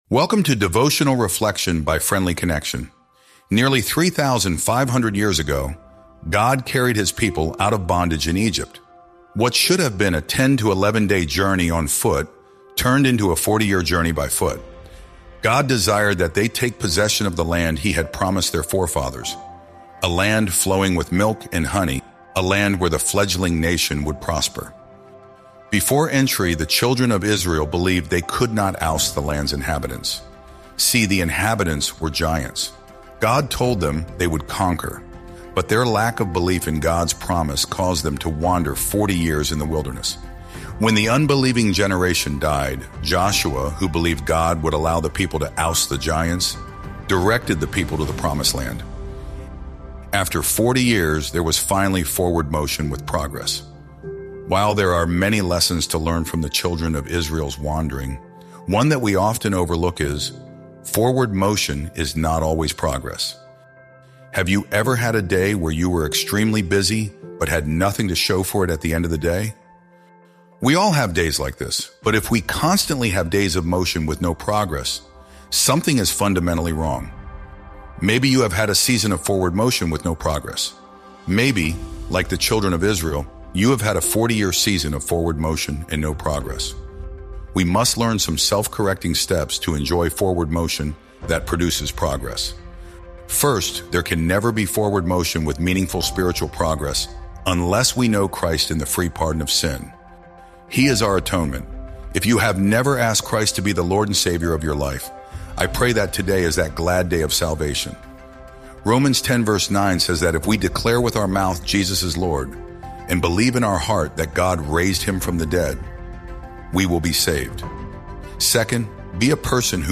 Sermons | Honey Creek New Providence Friends Church